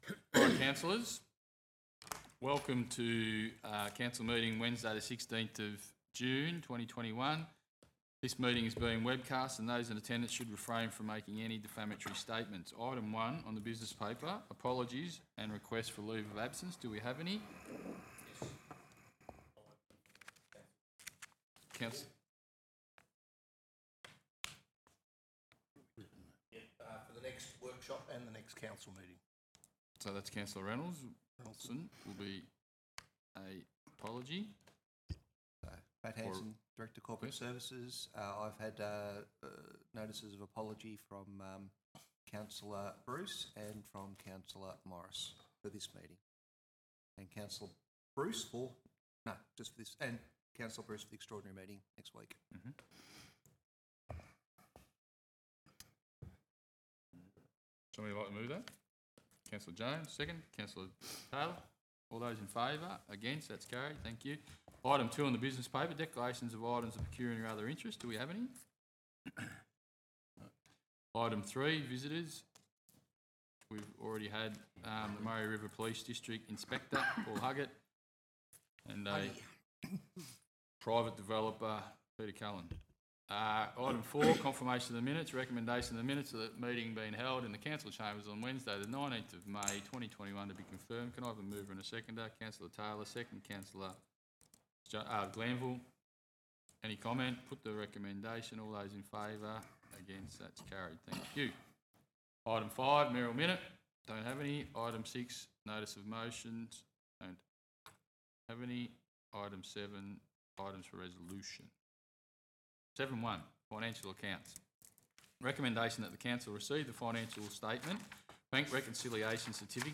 Meetings are held in the Council Chambers at 56 Chanter Street Berrigan